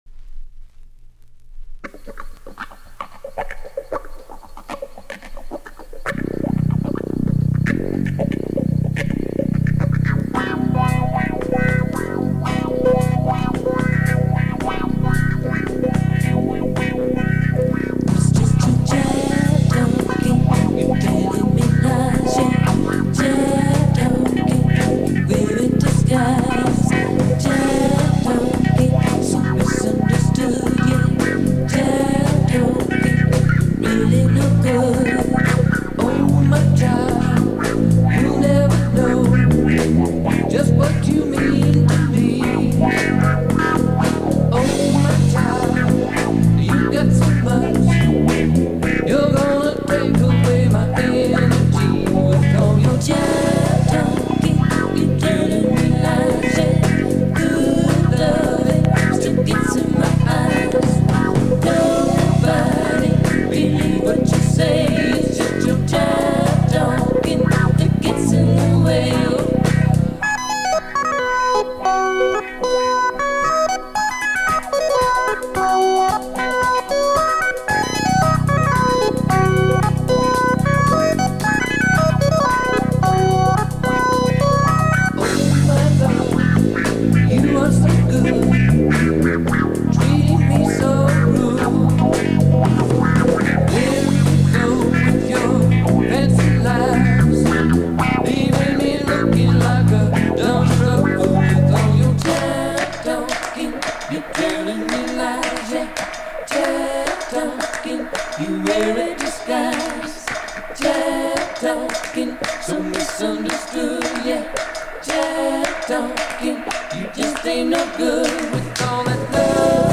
churns slowly and bubbles under the surface.